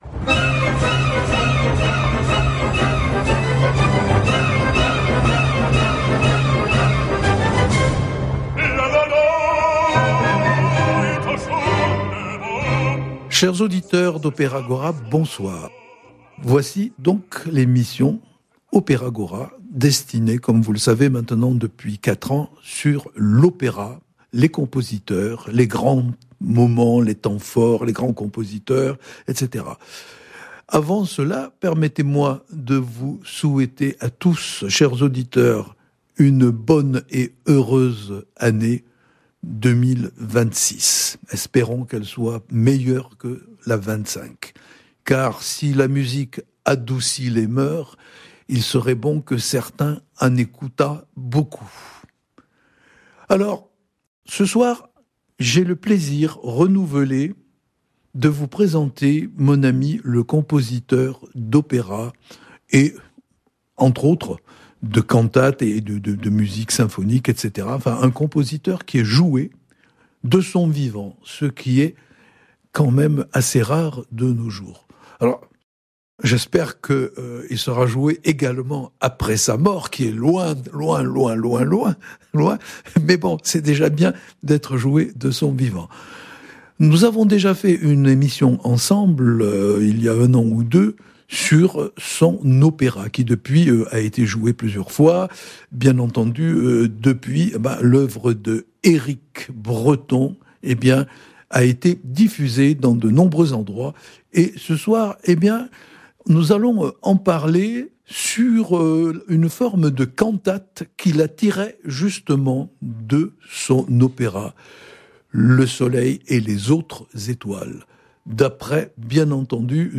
Interview
Les dessous de l’histoire…Présentation des principaux opéras et de leurs compositeurs avec extraits musicaux et anecdotes et rencontres des grands artistes rencontrés dans ma carrière.